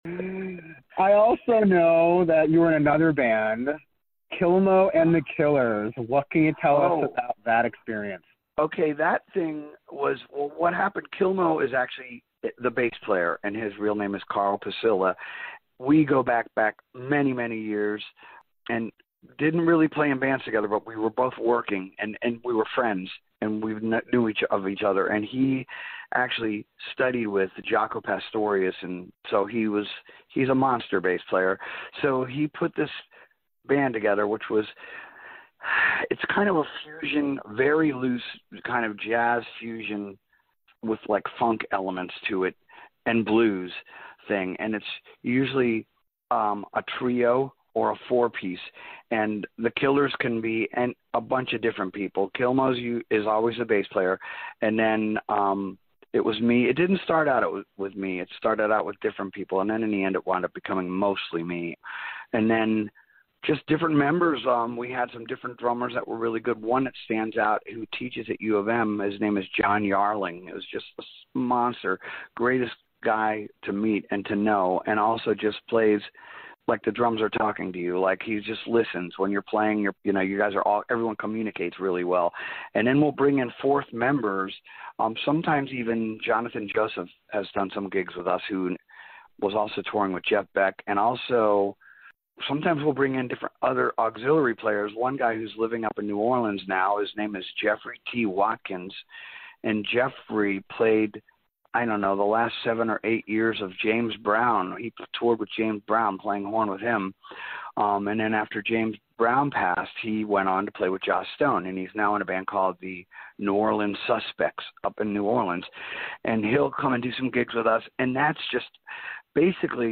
Florida Sound Archive Interview